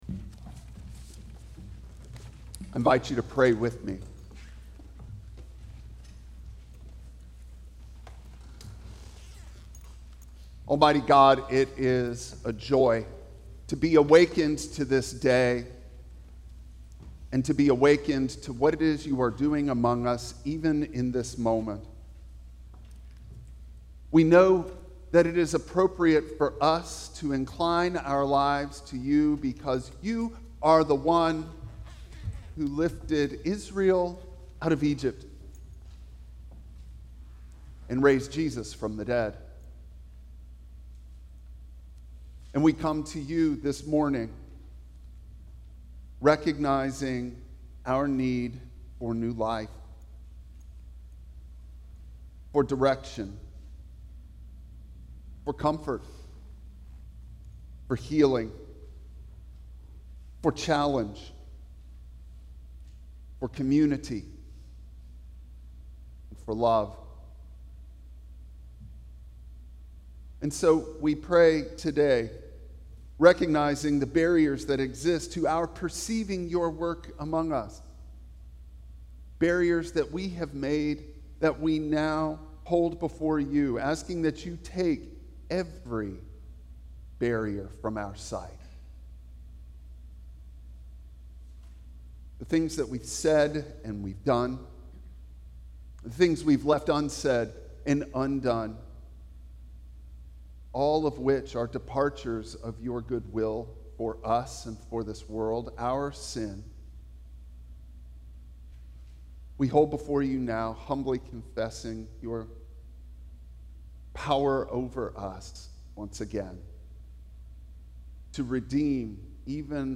Passage: Exodus 13:17-22 Service Type: Traditional Service Bible Text